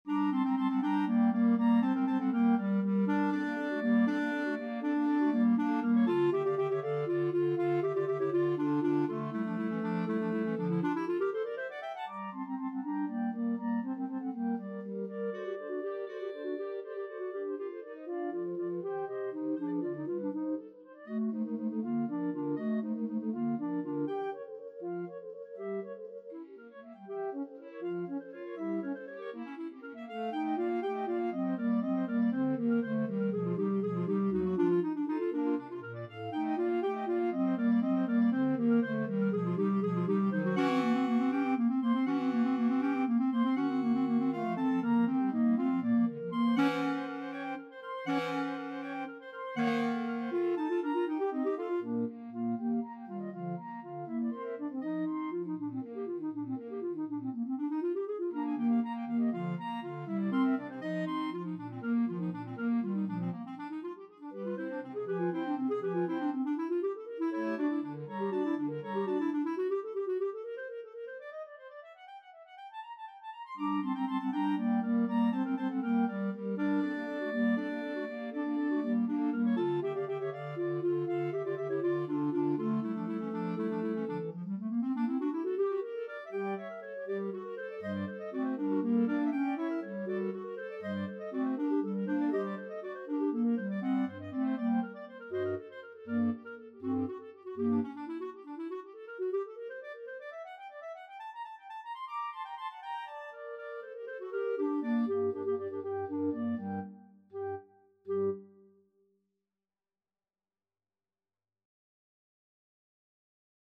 Clarinet 1Clarinet 2Clarinet 3Bass Clarinet
3/8 (View more 3/8 Music)
. = 80 Allegro Molto Vivace (View more music marked Allegro)
Classical (View more Classical Clarinet Quartet Music)